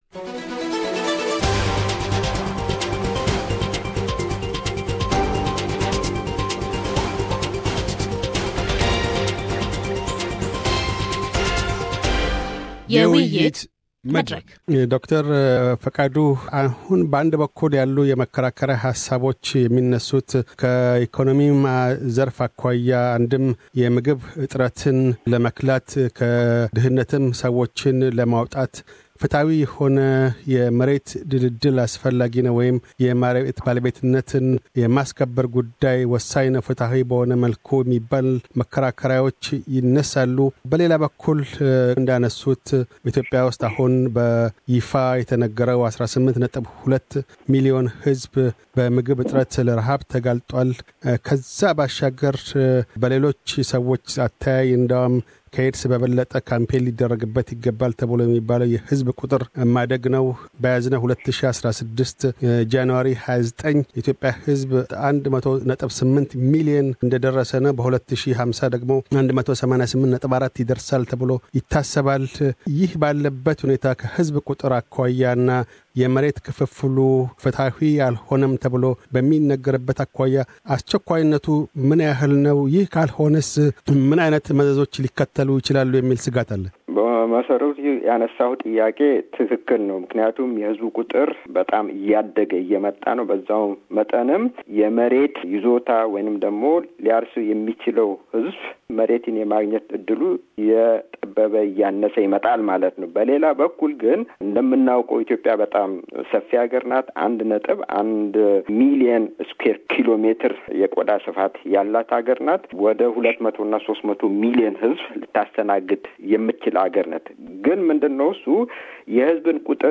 Panel discussion: Does Ethiopia need a new land reform? – Pt 3